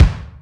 Kick
Original creative-commons licensed sounds for DJ's and music producers, recorded with high quality studio microphones.
Kick Drum Sound G# Key 40.wav
steel-kick-drum-sample-g-sharp-key-69-2WG.wav